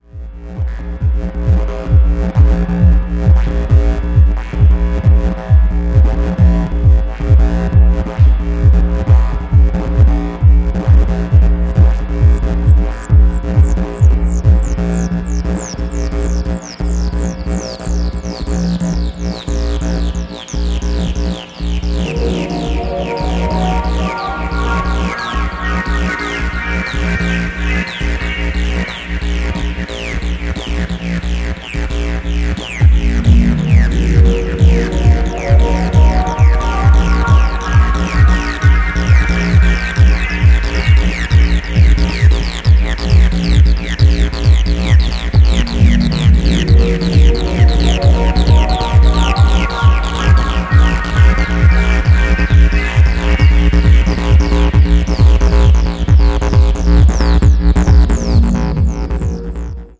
Acid infused techno.